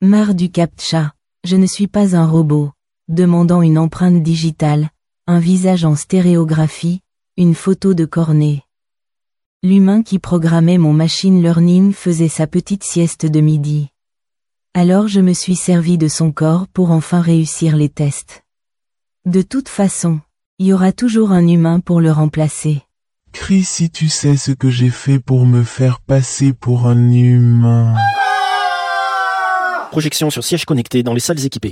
Fausse publicité : Je ne suis pas un robot
[Voix humaine très rapide] Projection sur sièges connectés dans les salles équipées.
Lecture : Android